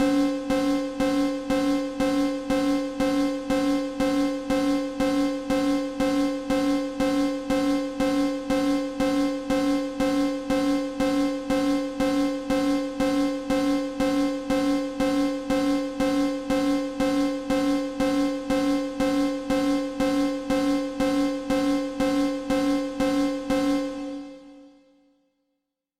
alarm_1.mp3